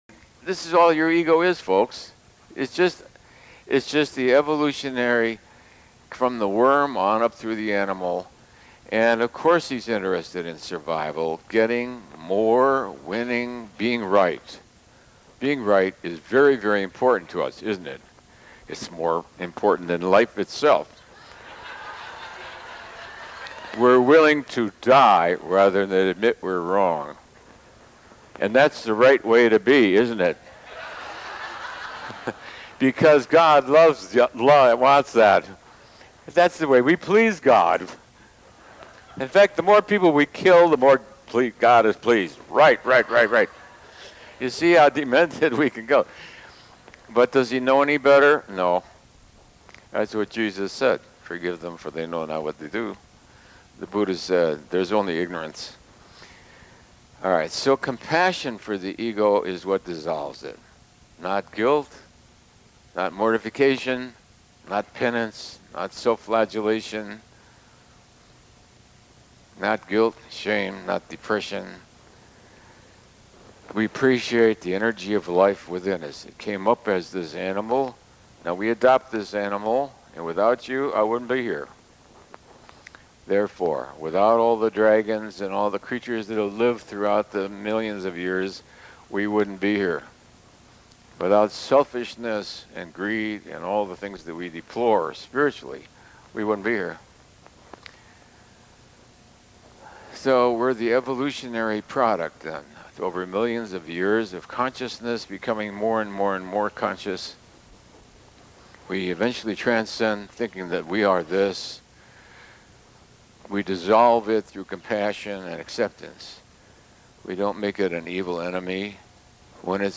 z úvodného slova, asi z Transcending the Ego
DRH vysvetľuje, že ego môžeme preklenúť, a dosiahnuť vyššie stavy vedomia nie tým, že s ním bojujeme, že strávime 30 rokov na psychoterapii alebo že budeme večne meditovať, ale tým, že porozumieme jeho pôvod, jeho povahu a funkciu. Ego sa potom začne rozpúšťať samo od seba prostredníctvom súcitu a prijatia, pretože keď posvietite na tmu, tma začne miznúť.